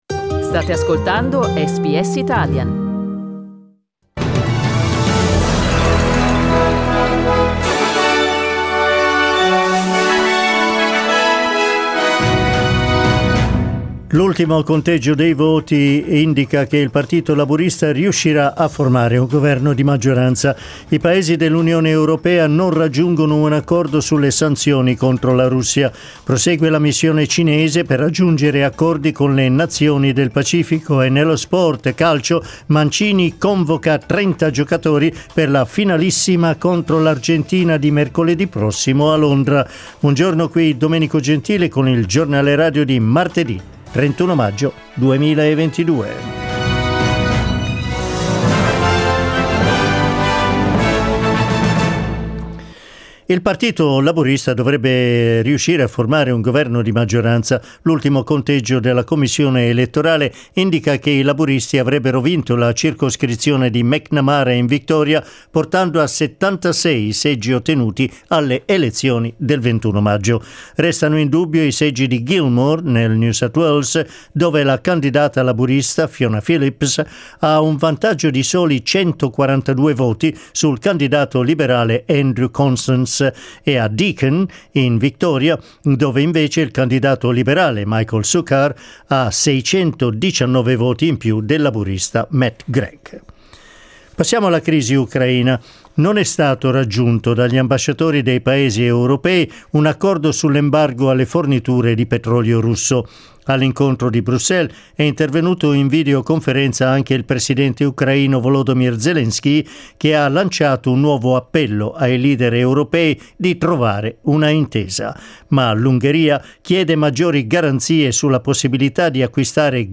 Giornale radio di martedì 31 maggio 2022
Il notiziario di SBS in italiano.